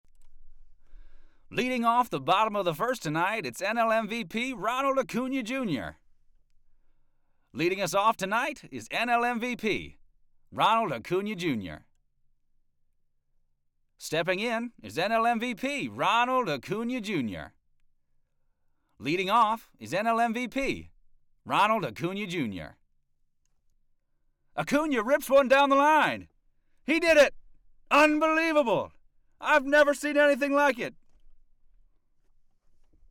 THEN, THE RECORDING FROM THE HIRED ACTOR TO READ TO THE CLIENT’S NEW SCRIPT.